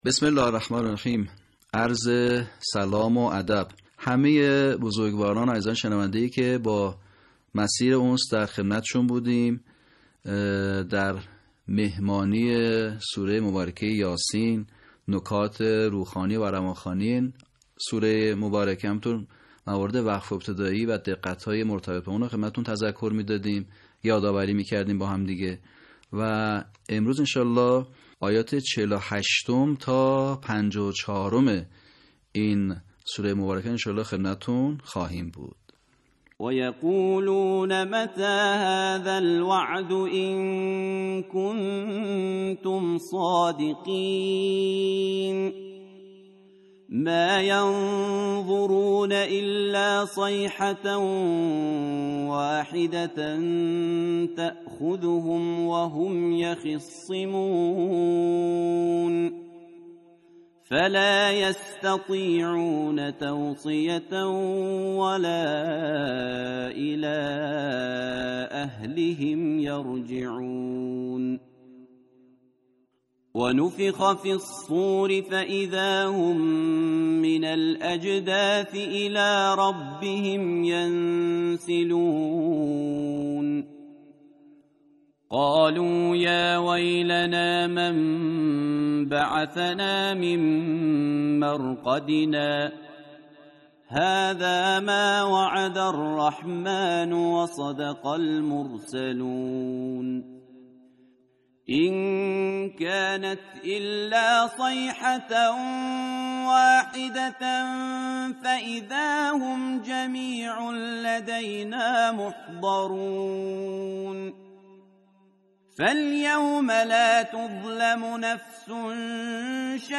صوت | صحیح‌خوانی آیات ۴۸ تا ۵۴ سوره یس
به همین منظور مجموعه آموزشی شنیداری (صوتی) قرآنی را گردآوری و برای علاقه‌مندان بازنشر می‌کند.